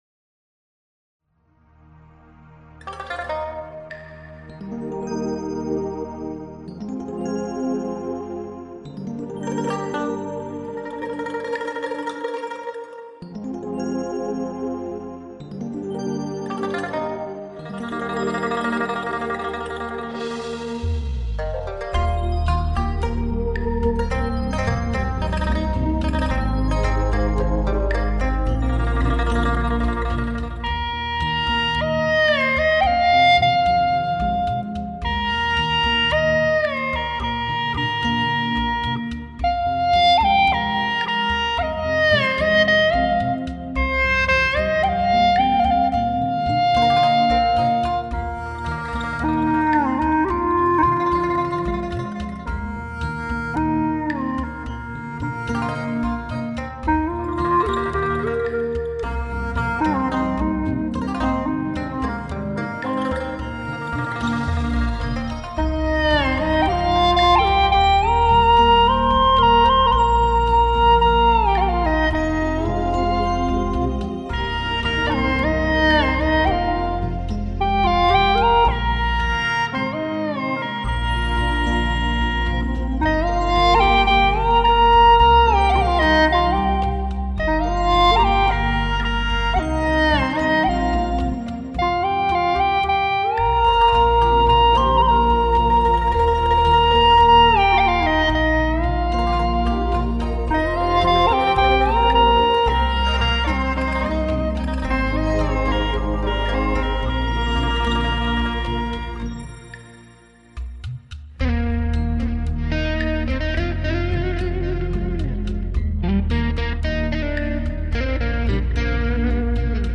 调式 : 降E 曲类 : 民族
纳西族民歌